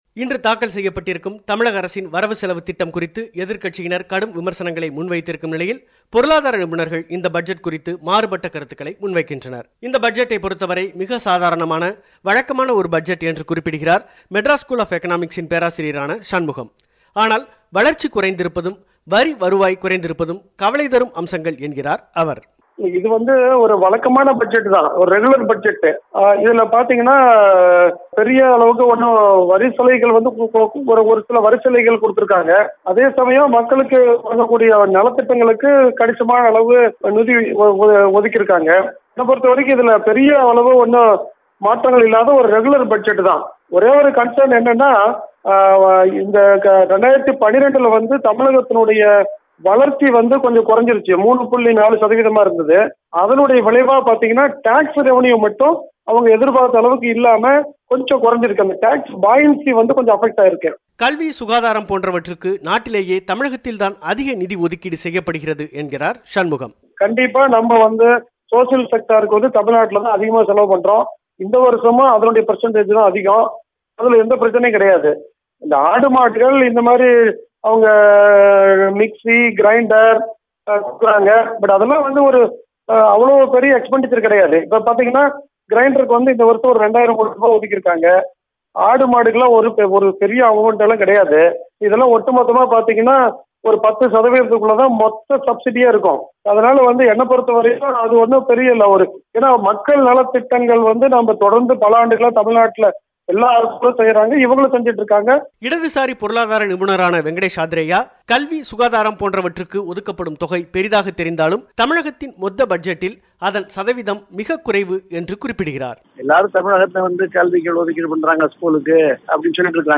புதிய வரிவிதிப்புகள் ஏதும் இல்லாத வரவு - செலவு அறிக்கையை தமிழக முதலமைச்சர் ஒ. பன்னீர்செல்வம் இன்று புதன்கிழமை தமிழக சட்டப்பேரவையில் தாக்கல் செய்திருக்கும் நிலையில் அதன் சாதக பாதகங்கள் மற்றும் சரிந்துவரும் தமிழக அரசின் வரி வருவாய்க்கான காரணங்களை விளக்கும் செய்திப்பெட்டகம்